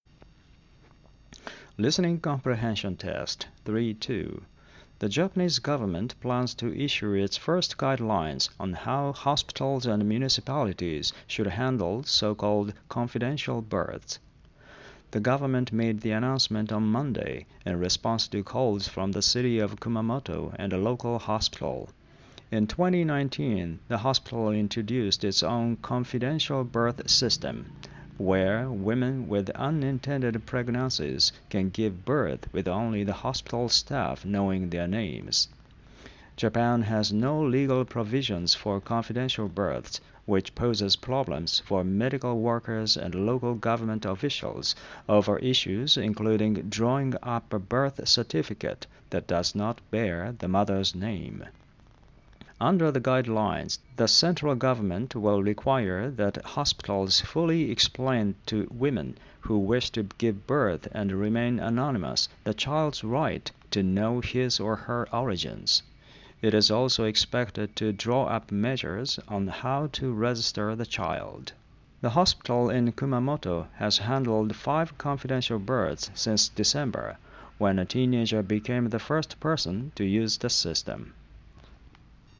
これは著作権の関係で僕が読んでいますが